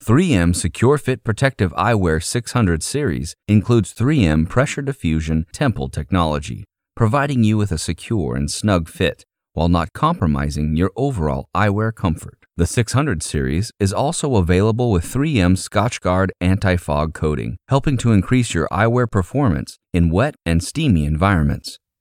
Mature Adult, Adult, Young Adult
Has Own Studio
southern us
standard us
authoritative
dramatic
friendly